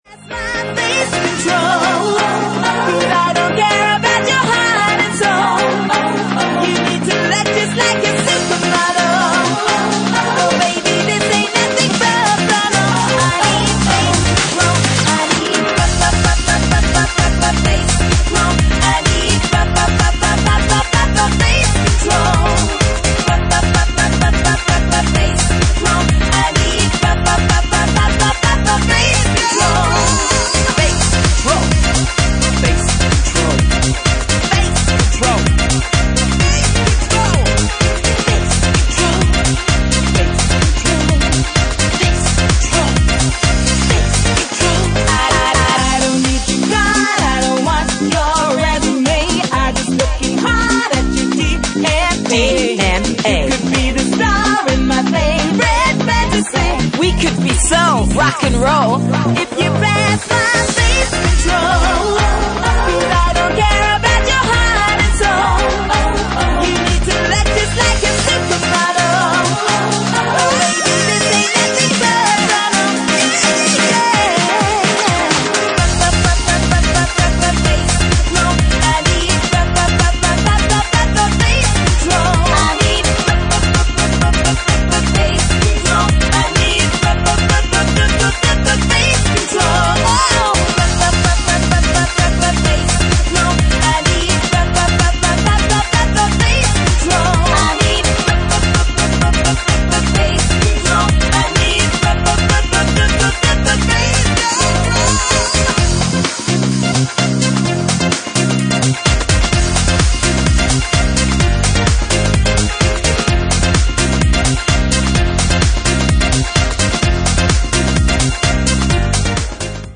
Genre:Electroline
Electroline at 128 bpm